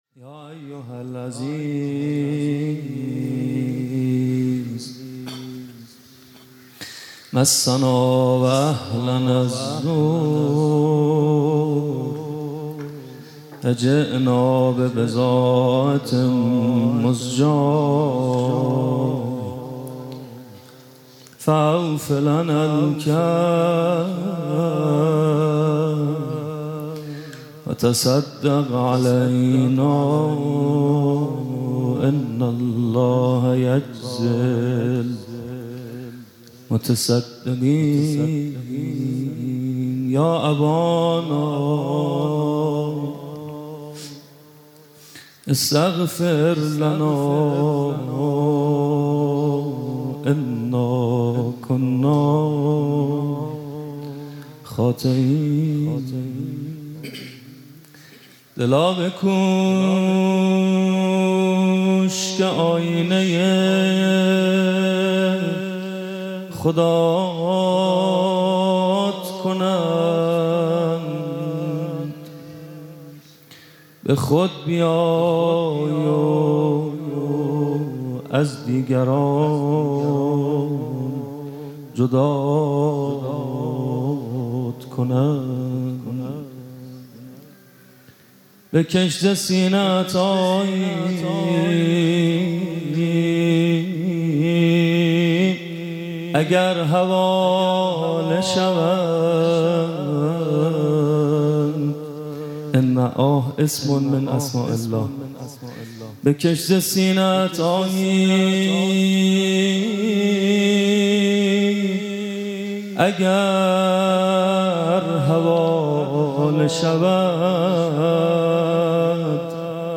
جلسه هفتگی | یادواره شهدای انقلاب اسلامی ۱۴۰۲
سخنرانی